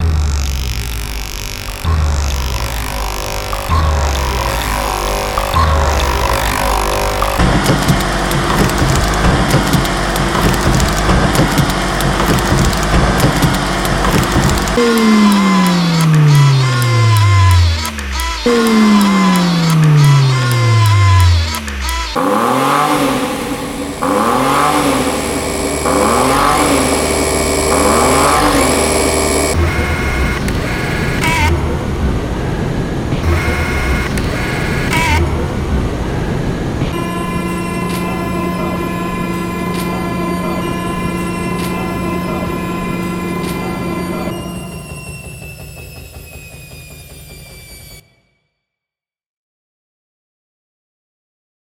پکیج افکت صوتی حلقه های مکانیکی
Whether your stood on a train at rush hour, boiling the kettle or waiting for your washing to stop spinning, there are mechanical rhythms waiting to be captured and used creatively. This pack is full of industrial machinery and everyday objects churning away and creating their own patterns.
Mechanical.Loops.mp3